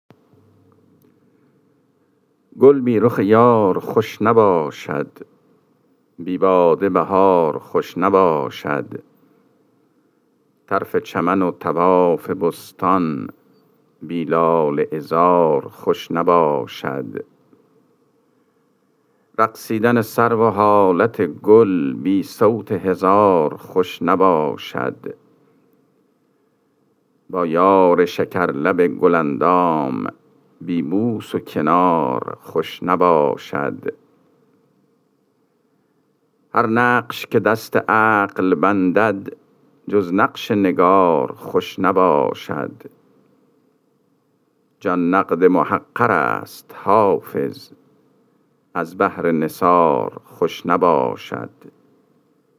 خوانش غزل شماره ۱۶۳ دیوان حافظ